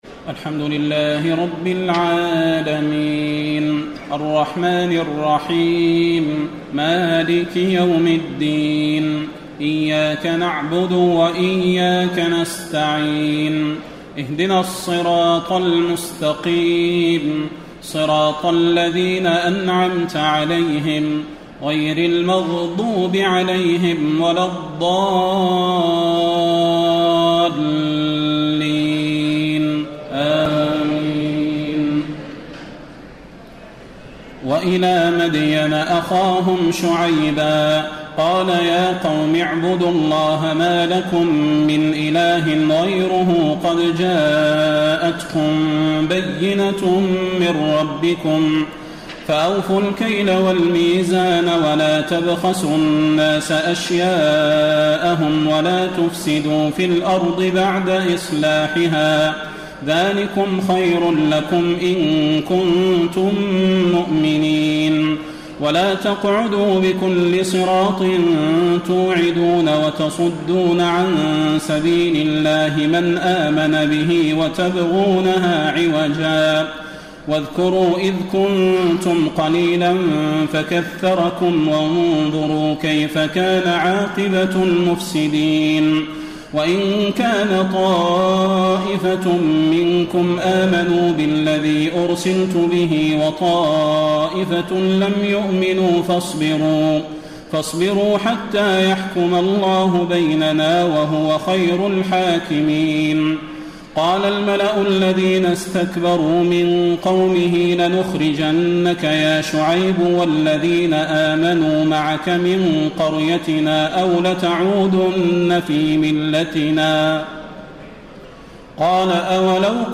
تهجد ليلة 29 رمضان 1434هـ من سورة الأعراف (85-188) Tahajjud 29 st night Ramadan 1434H from Surah Al-A’raf > تراويح الحرم النبوي عام 1434 🕌 > التراويح - تلاوات الحرمين